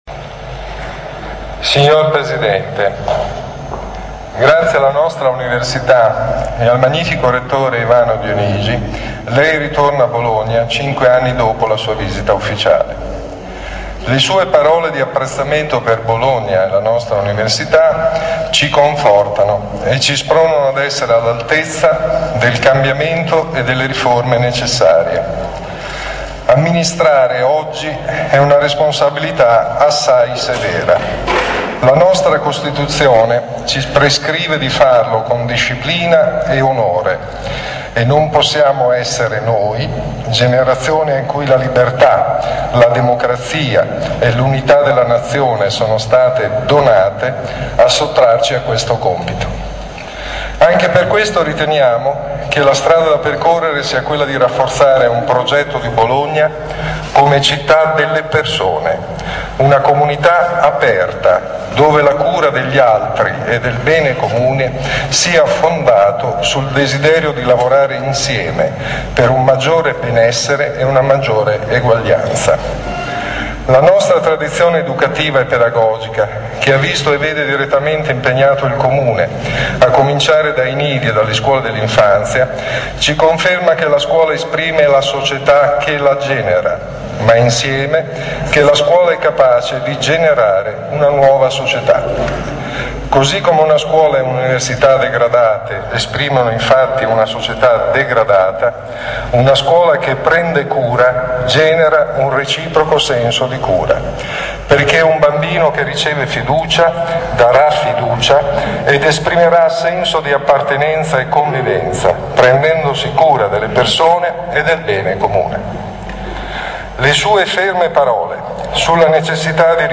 Martedì 31 gennaio 2012 il Presidente della Repubblica Giorgio Napolitano ha incontrato in Sala d'Ercole di Palazzo d'Accursio i membri delle giunte e dei consigli comunale, provinciale e regionale.